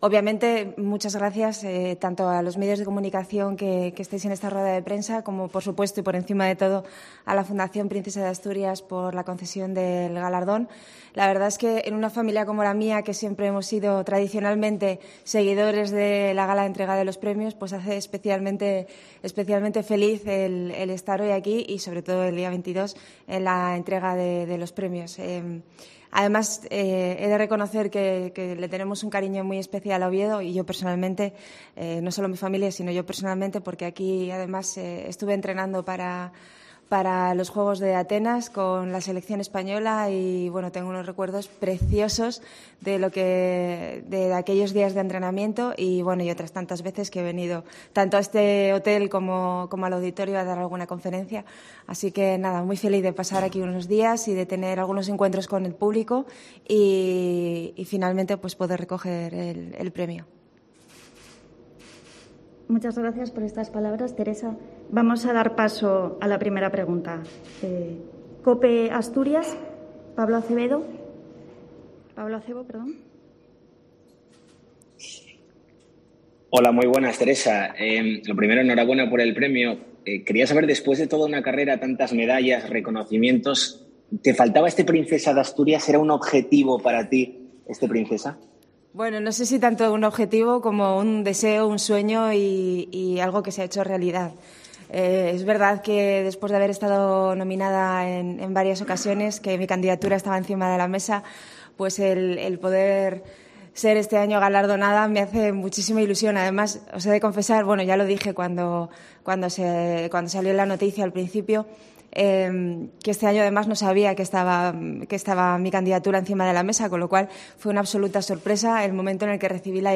Rueda de prensa Teresa Perales (Premio Princesa de Asturias Deportes)